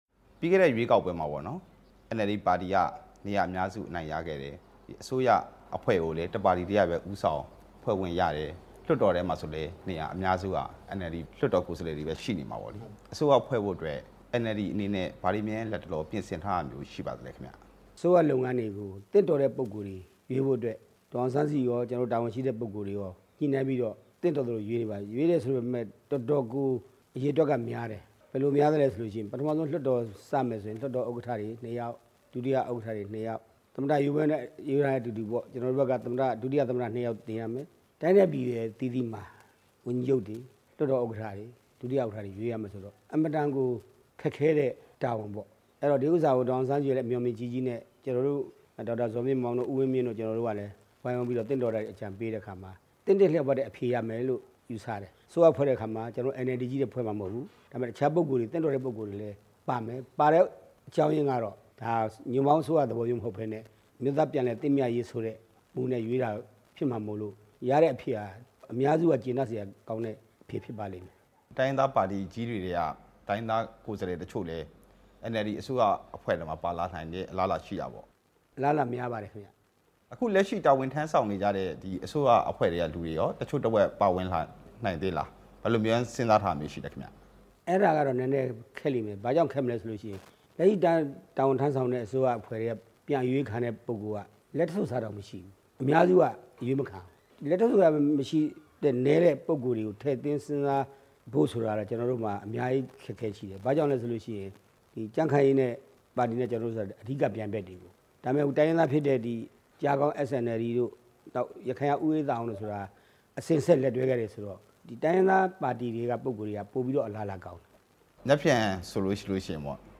အမျိုးသားဒီမိုကရေစီအဖွဲ့ချုပ်က ဦးဆောင်မယ့် အစိုးရ ၅ နှစ်တာကာလအတွင်း ပုဒ်မ ၄၃၆နဲ့ ၅၉ (စ) တွေကို ပြင်နိုင် ပြီး ဒေါ်အောင်ဆန်းစုကြည်ဟာ နိုင်ငံတော် သမ္မတ အဖြစ်တာဝန်ထမ်းဆောင်နိုင်လာမယ်လို့ ယုံကြည်ကြောင်း အမျိုးသားဒီမိုကရေစီ အဖွဲ့ချုပ် NLD ပါတီရဲ့ ဗဟိုအလုပ်မှုဆောင်ကော်မတီဝင် ဦးဝင်းထိန် က RFA ကို ဒီကနေ့ ပြော လိုက်ပါတယ်။